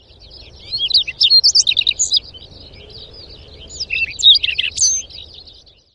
Calliope calliope 野鴝 別名： 紅喉歌鴝 學名： Calliope calliope,